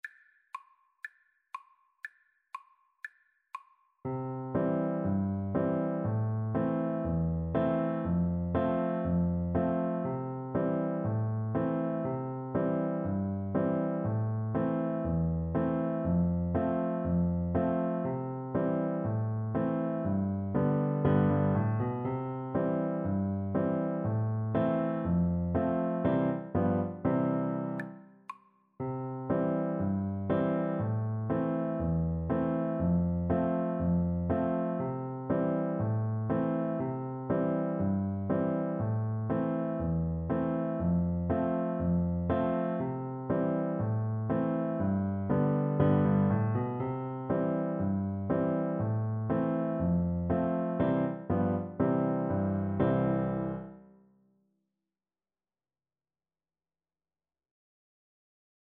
Play (or use space bar on your keyboard) Pause Music Playalong - Player 1 Accompaniment transpose reset tempo print settings full screen
C major (Sounding Pitch) (View more C major Music for Piano Duet )
Piano Duet  (View more Easy Piano Duet Music)